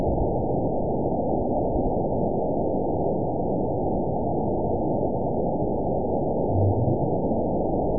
event 920462 date 03/26/24 time 20:13:34 GMT (1 year, 1 month ago) score 9.20 location TSS-AB02 detected by nrw target species NRW annotations +NRW Spectrogram: Frequency (kHz) vs. Time (s) audio not available .wav